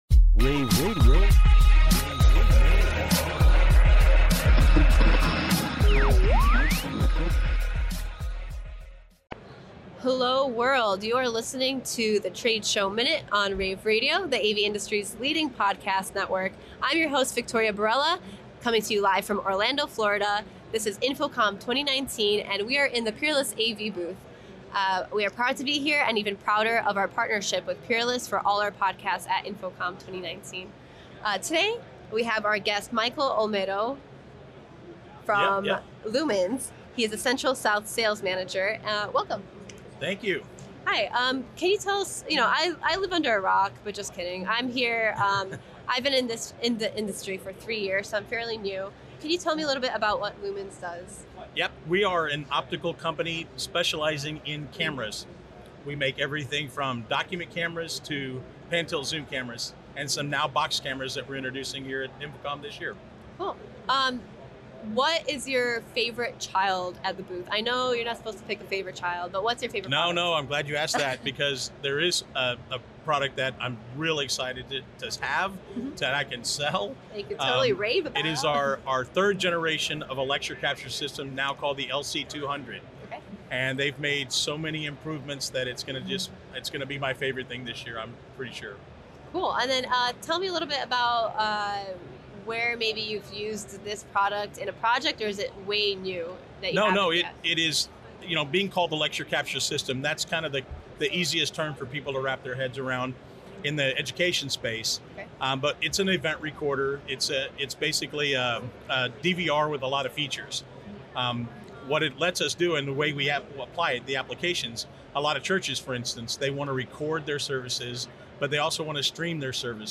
June 13, 2019 - InfoComm, InfoComm Radio, Radio, rAVe [PUBS], The Trade Show Minute,